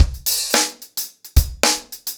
DaveAndMe-110BPM.33.wav